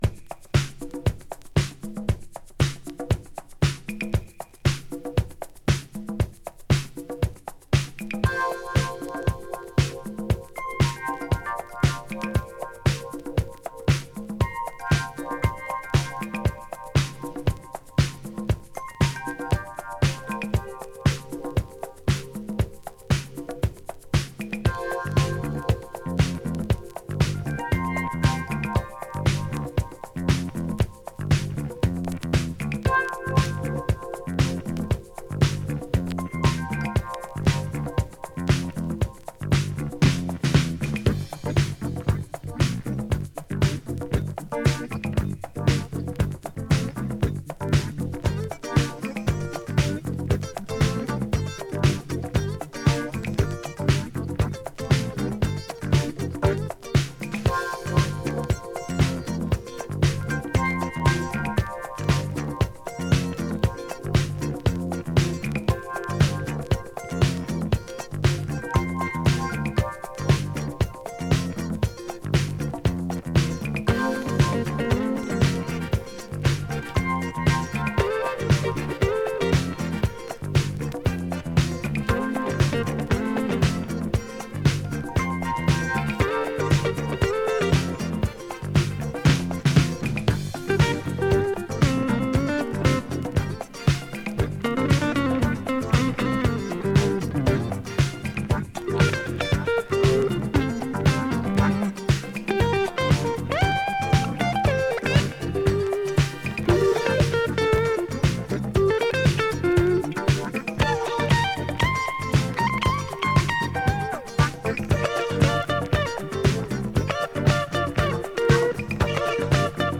和フュージョン～ポップス・シーンを代表するギタリスト。
【FUSION】【BOOGIE】